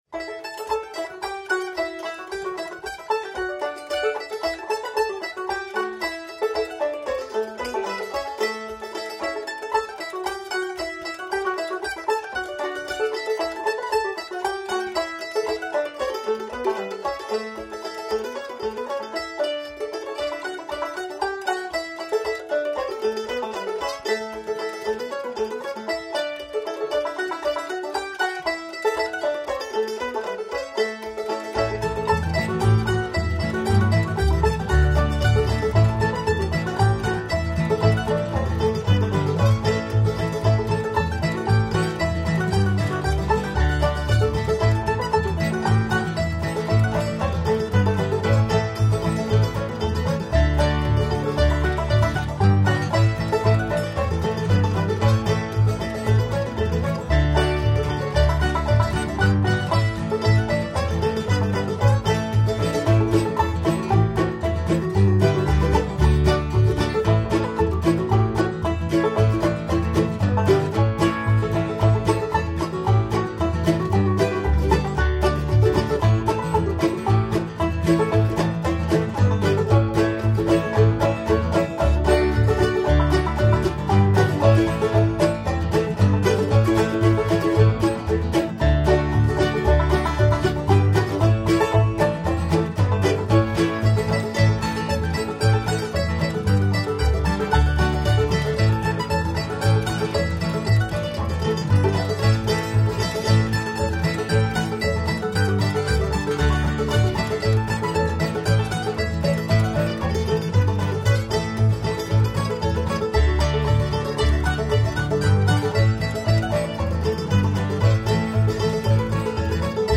Little Rabbit is a band that performs old-time American songs and fiddle tunes.
Texas: an old fiddle tune from a band with no fiddles.
banjo
guitar
bass
mandolin
All of us sing.